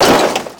car_light_4.wav